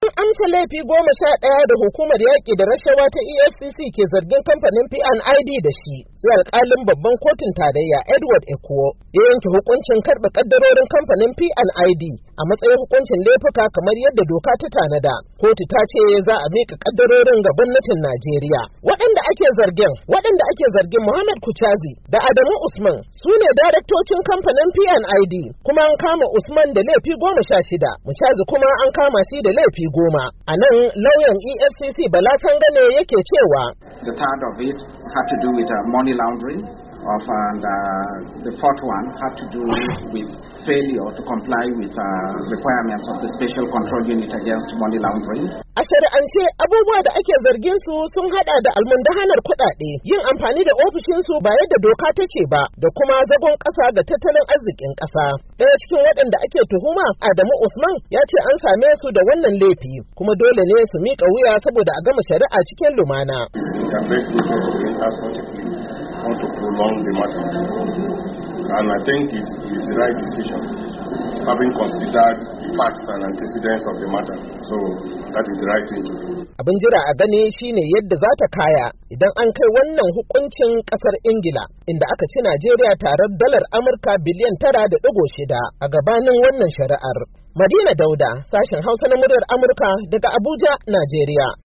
Ga cikakken rahoto